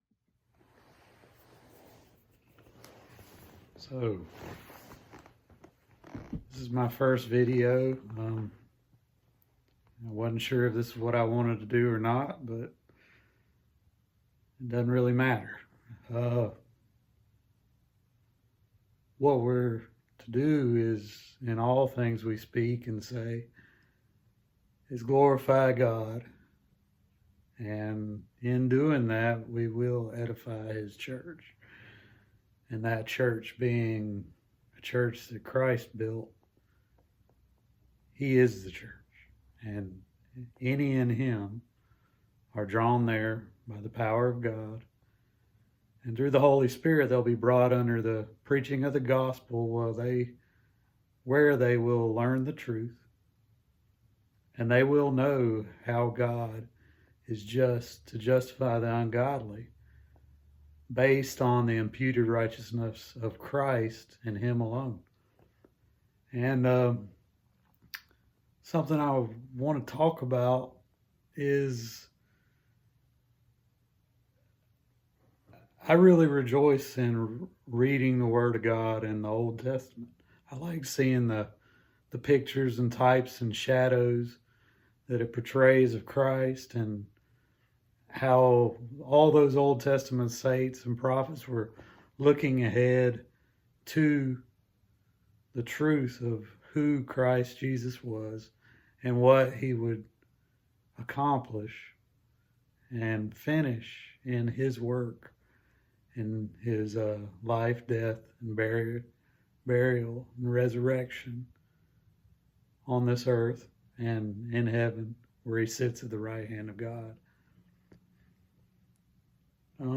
Rejoicing in the Truth | SermonAudio Broadcaster is Live View the Live Stream Share this sermon Disabled by adblocker Copy URL Copied!